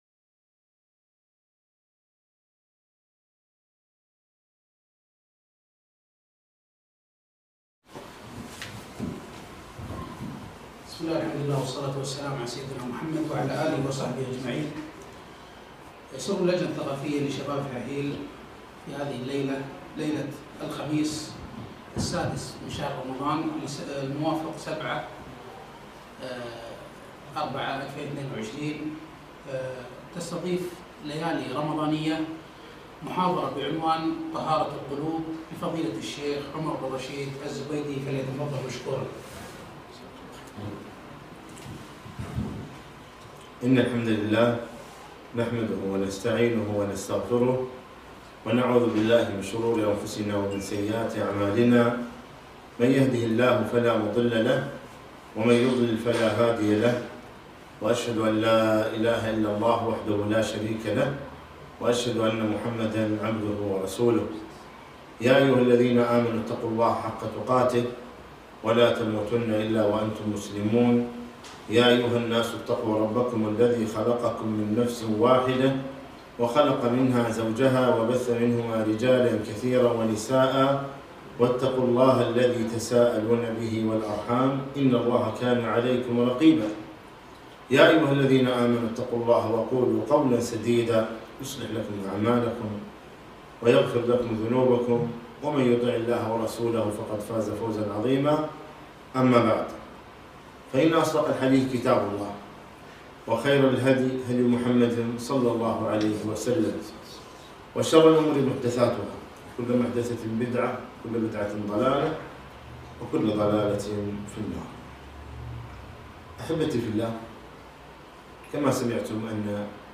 محاضرة - طهارة القلوب